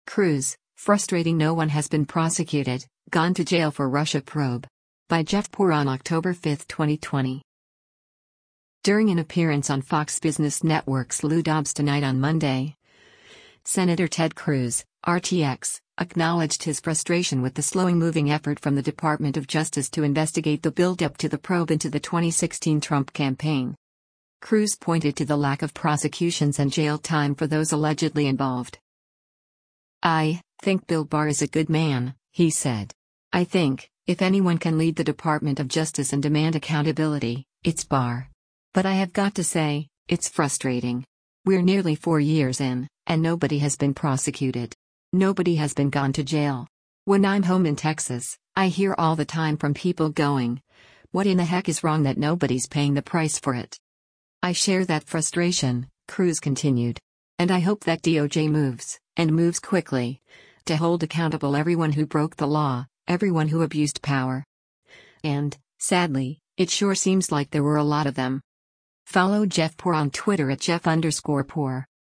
During an appearance on Fox Business Network’s “Lou Dobbs Tonight” on Monday, Sen. Ted Cruz (R-TX) acknowledged his frustration with the slowing-moving effort from the Department of Justice to investigate the build-up to the probe into the 2016 Trump campaign.